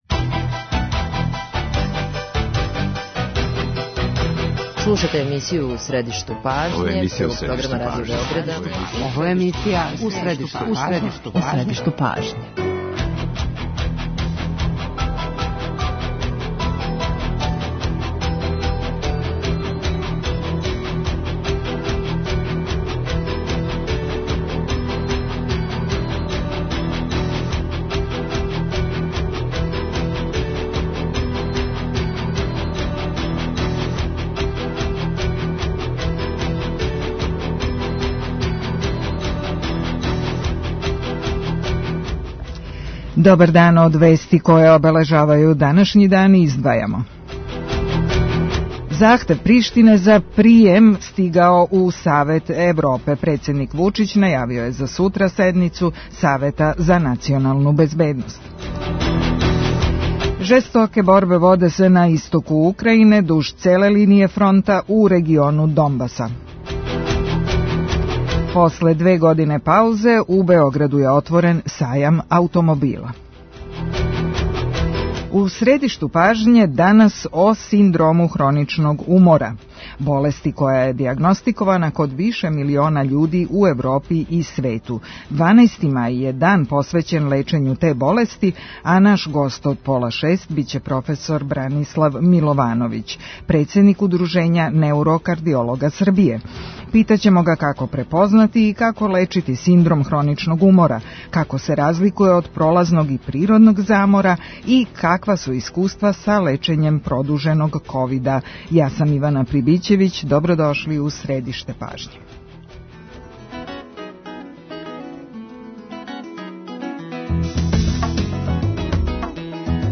доноси интервју